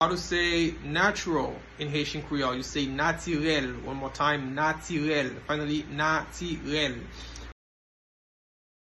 Listen to and watch “Natirèl” pronunciation in Haitian Creole by a native Haitian  in the video below:
Natural-in-Haitian-Creole-Natirel-pronunciation-by-a-Haitian-teacher.mp3